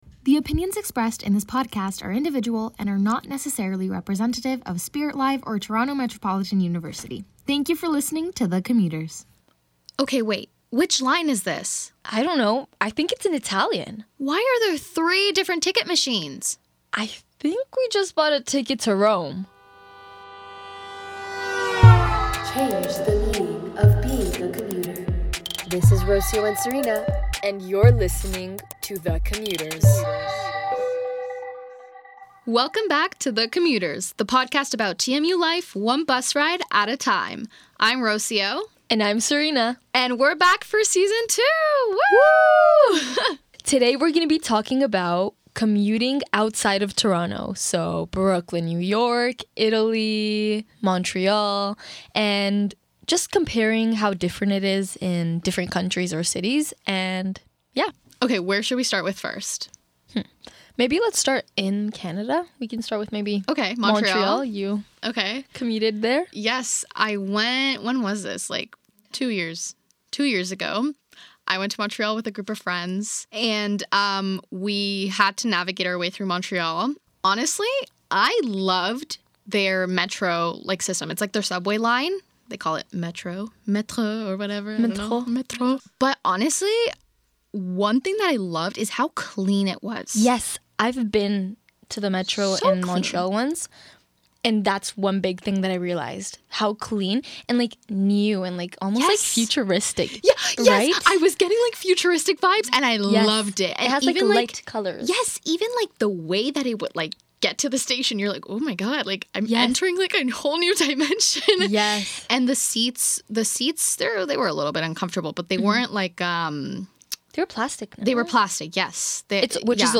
The Commuters is a student podcast hosted by two cousins, navigating TMU student life on the go, with stories, laughs, and real talk about what it means to survive (and thrive) as a commuter.